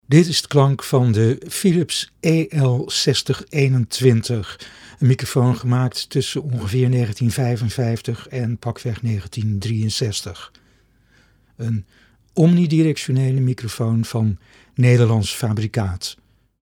De Philips EL 6021 is een omnidirectionele dynamische microfoon, van rond 1955.
Het waren zeker geen studiomicrofoons, maar hun klank met veel middentonen maakt dat ze tegenwoordig wel gebruikt kunnen worden voor een vintage geluid.
Philips EL 6021 Sound NL.mp3